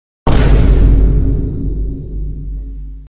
Download Free Vine Boom Sound Effects